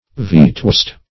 Vetoist \Ve"to*ist\, n. One who uses, or sustains the use of, the veto.